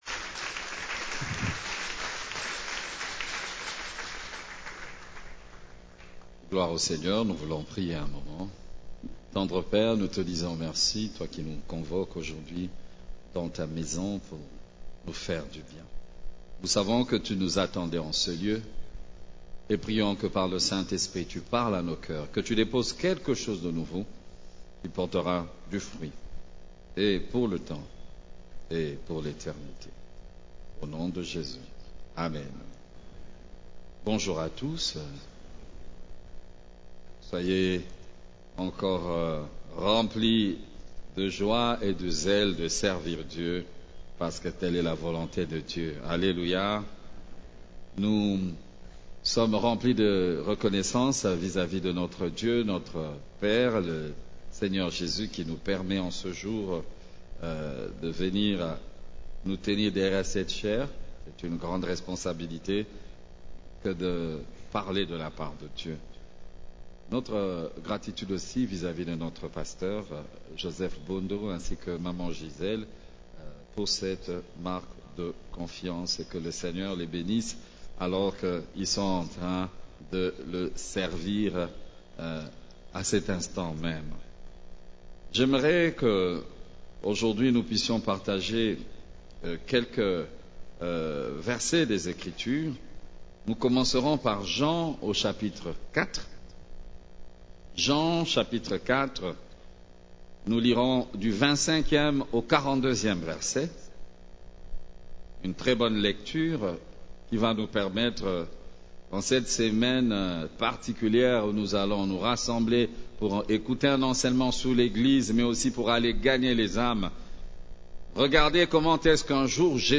CEF la Borne, Culte du Dimanche, Levez les yeux et regardez les champs: la moisson est mûre